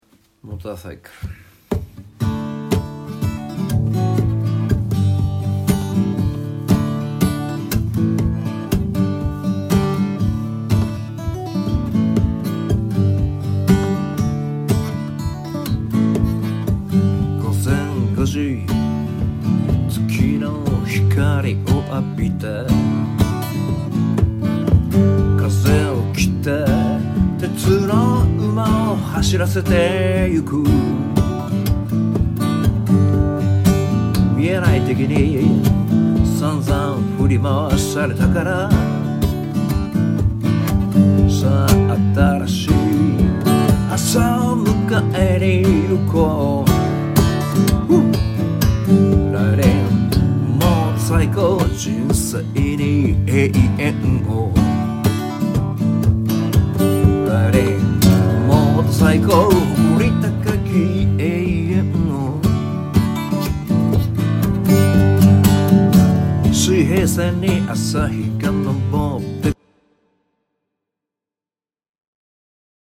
てなわけで、今日は特別にメンバーに渡った弾き語りの音源実物を。iPhoneのボイスメモでちゃちゃっと録ったものです。
これ、完成したものとキーも歌詞も違います。